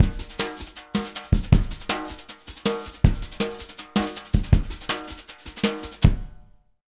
Index of /90_sSampleCDs/USB Soundscan vol.08 - Jazz Latin Drumloops [AKAI] 1CD/Partition A/06-160JUNGLB
160JUNGLE8-L.wav